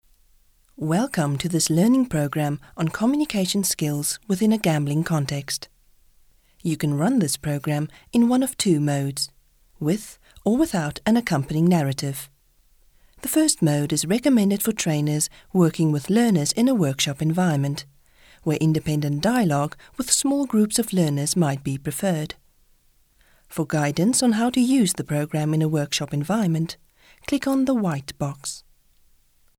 Afrikaans, South African English, warm, reassuring, authoritive
Sprechprobe: eLearning (Muttersprache):